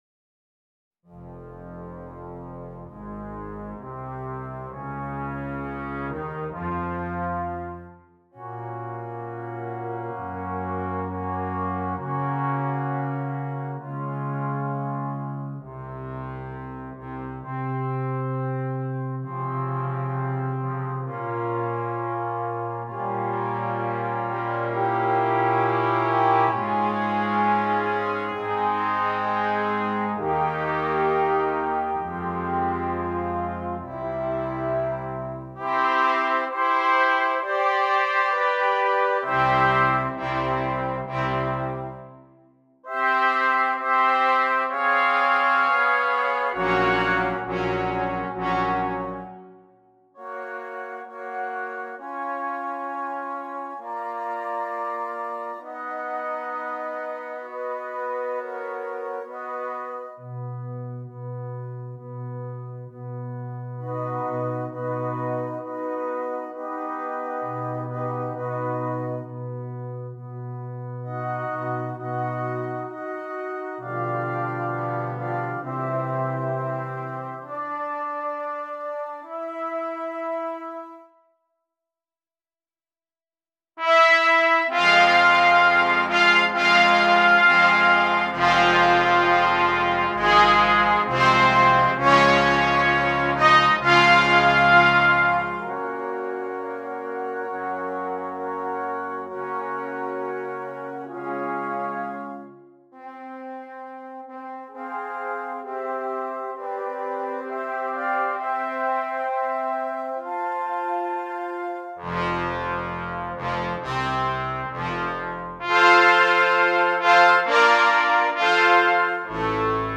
Brass Choir (4.2.3.0.1)